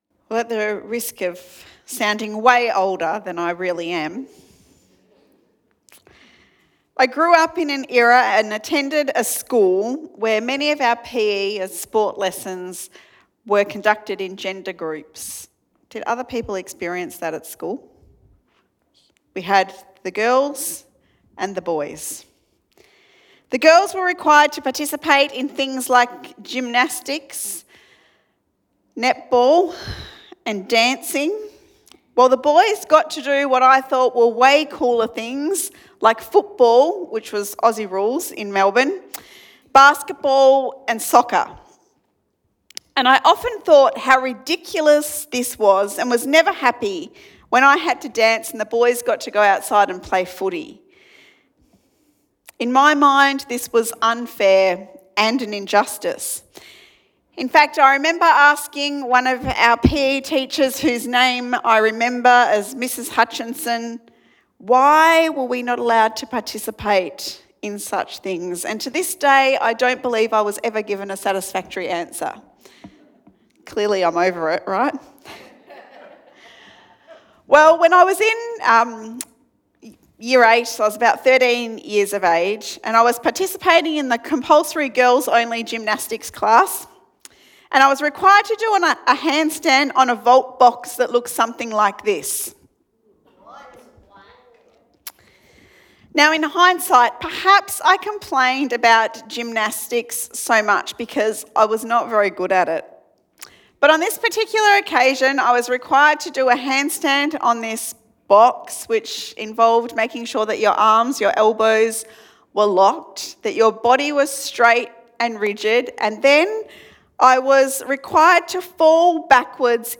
Sermon Podcasts Jesus & Justice